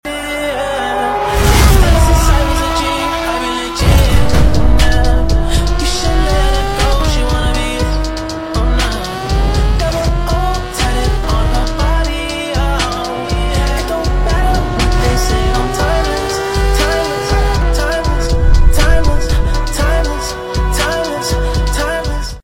Lava 🍊 991.2 GT3RS With JCR Exhaust 🔥🔊